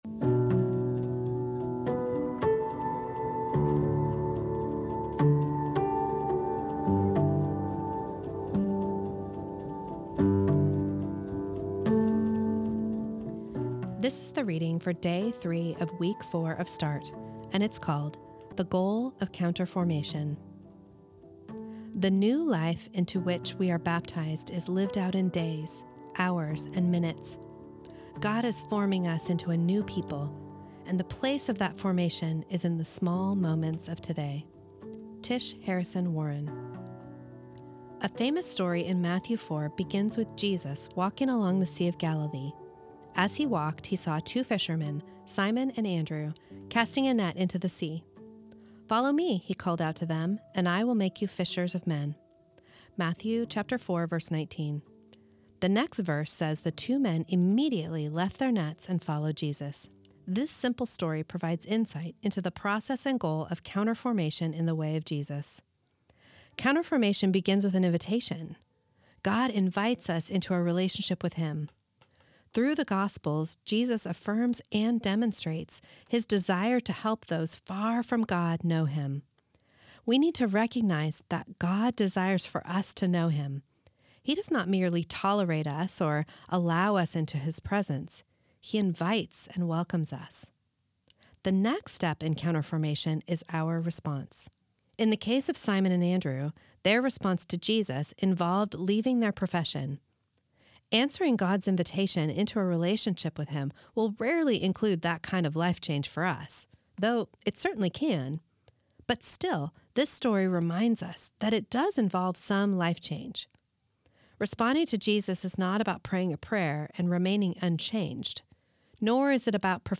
This is the audio recording of the third reading of week six of Start, entitled The Goal of Counter-formation.